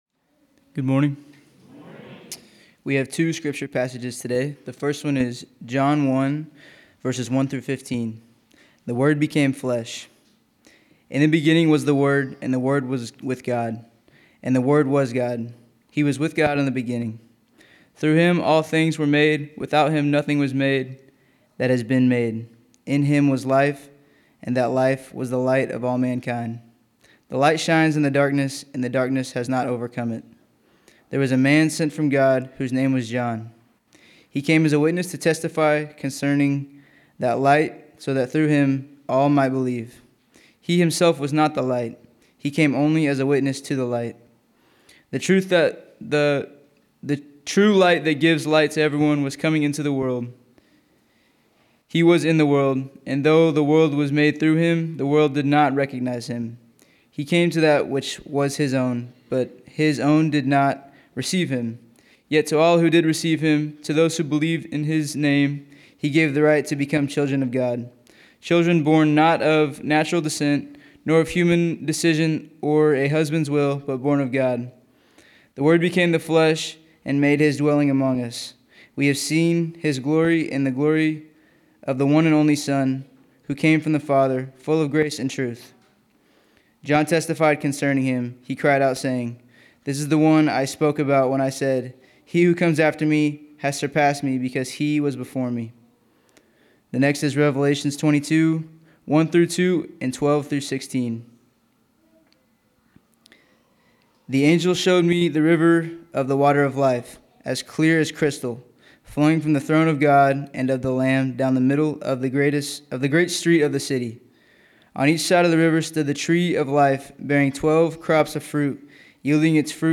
February 8, 2026 Sermon Audio